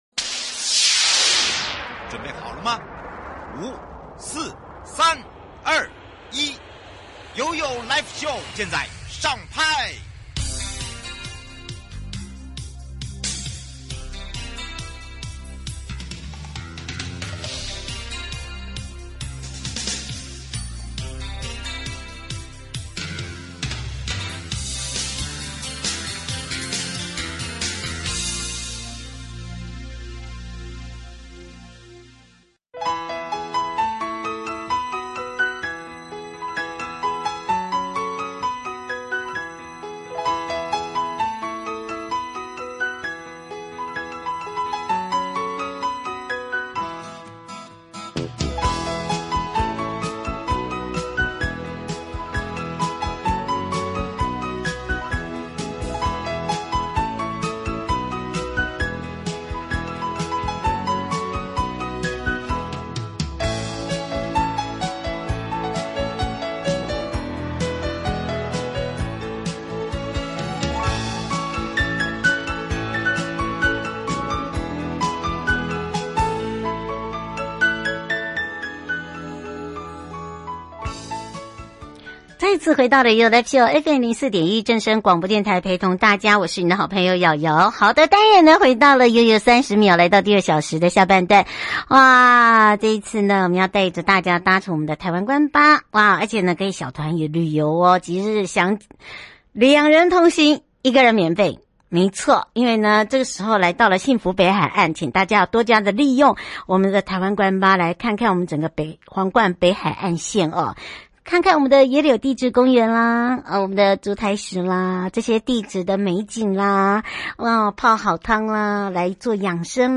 受訪者： 大鵬灣管理處 許主龍處長